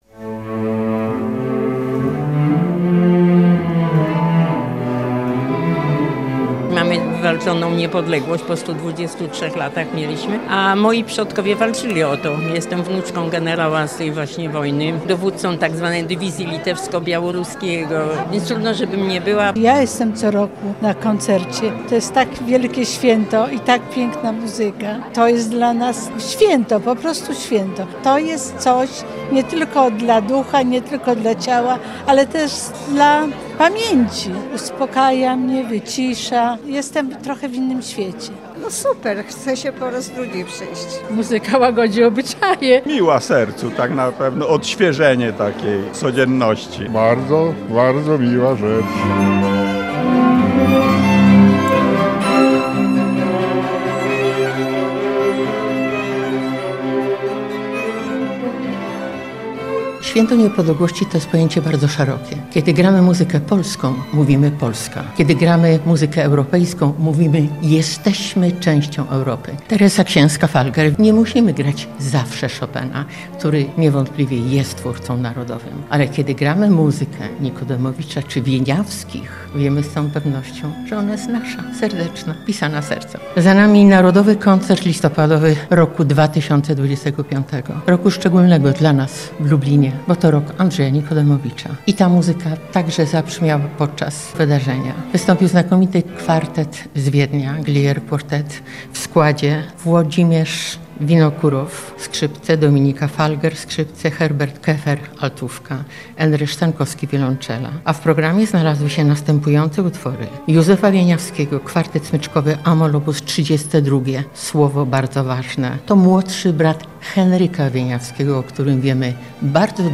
W Filharmonii Lubelskiej wystąpił znany i ceniony wiedeński kwartet smyczkowy Glière Quartet